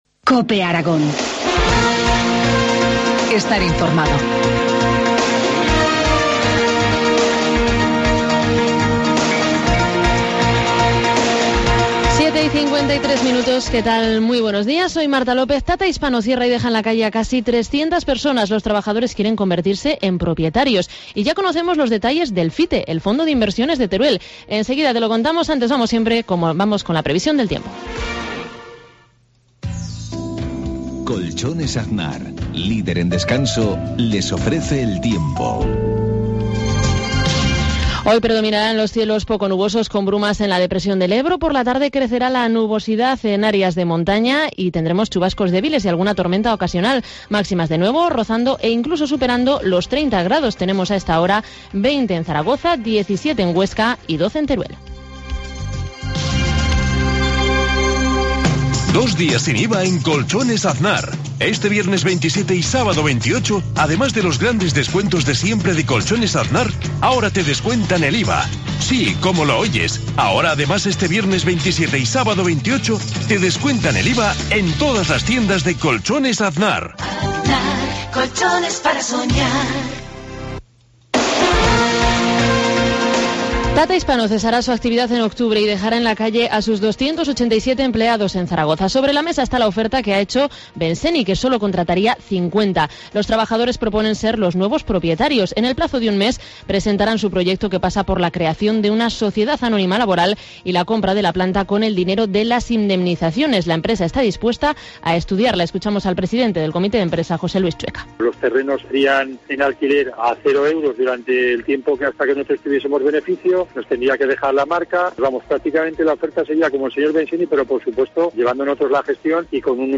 Informativo matinal, miércoles 25 de septiembre, 7.53 horas